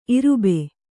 ♪ irube